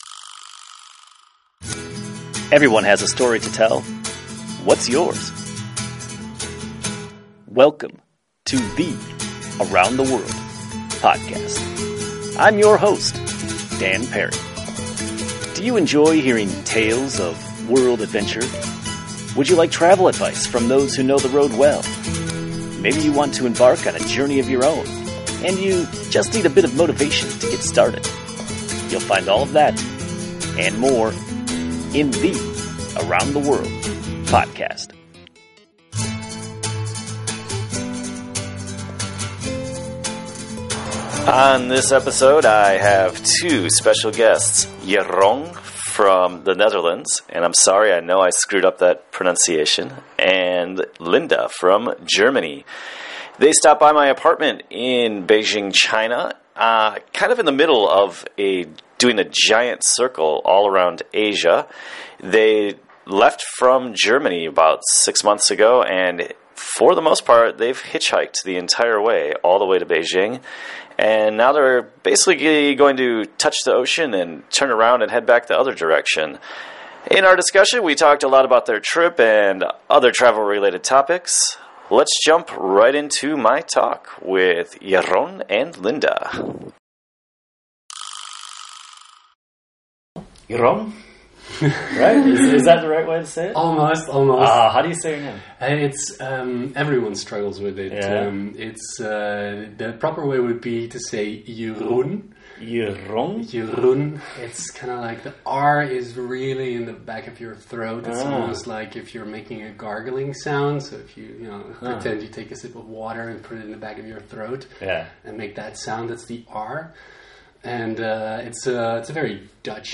I met them in my apartment in Beijing, when they were about halfway through their trip.